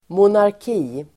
Ladda ner uttalet
Uttal: [monark'i:]